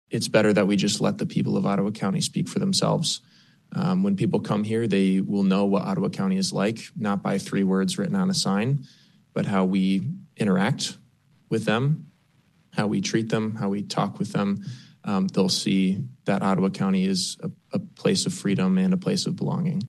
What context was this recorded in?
During Tuesday night’s monthly Board of Commissioners business meeting at the Fillmore Street complex in West Olive, a proposal to, “approve the elimination of the Ottawa County motto of ‘Where Freedom Rings'” passed by a 7-4 margin.